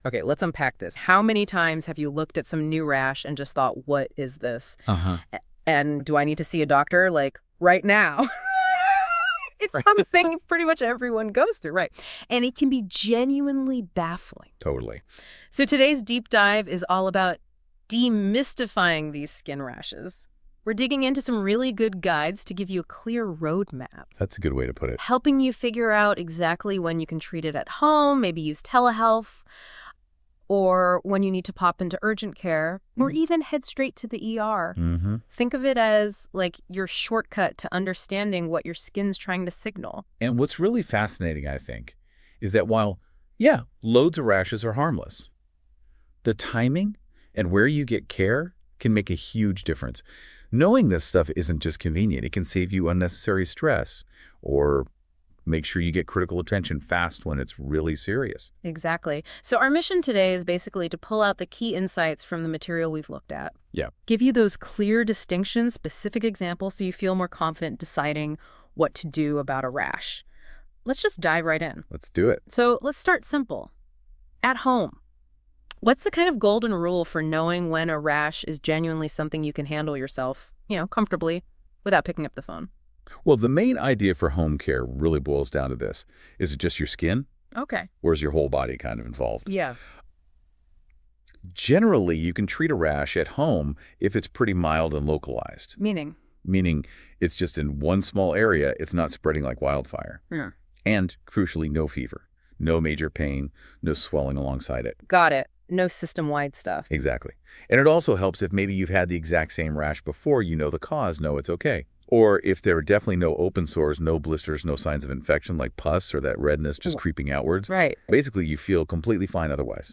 Listen to a discussion about when to go to urgent care for a rash